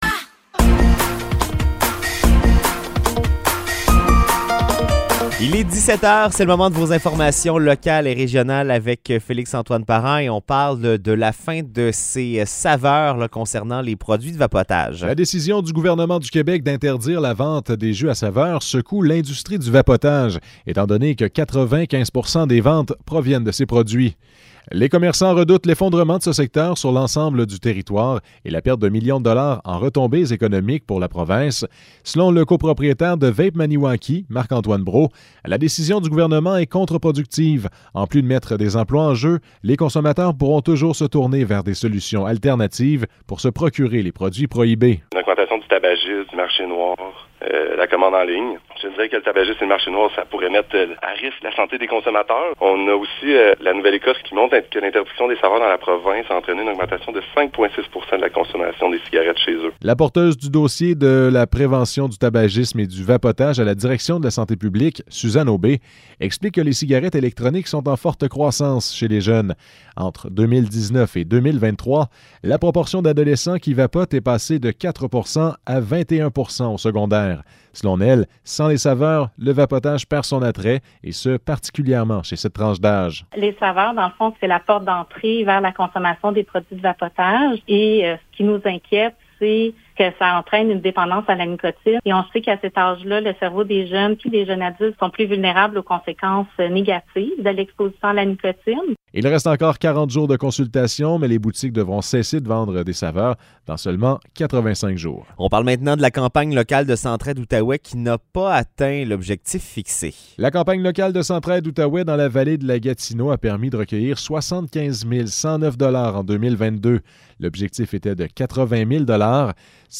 Nouvelles locales - 25 avril 2023 - 17 h